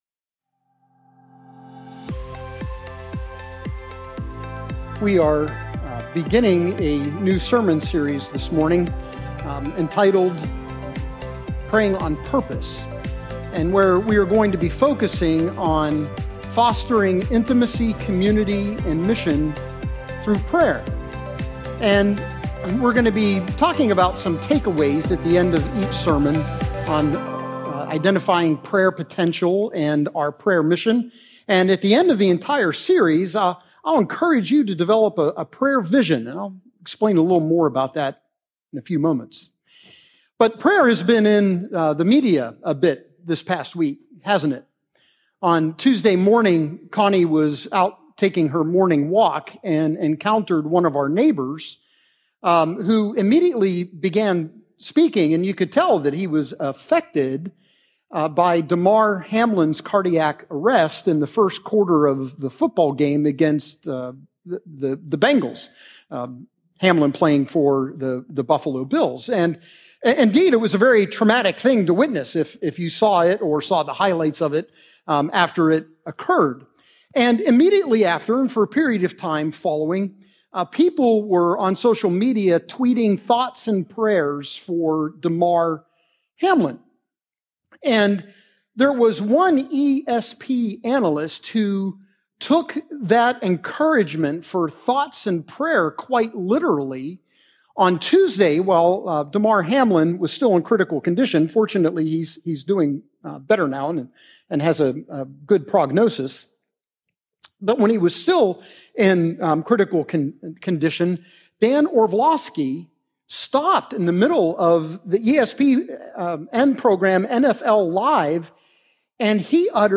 Join us at Westtown this Sunday as we begin a new sermon series on the Lord's prayer and see how being able to address God as our Father answers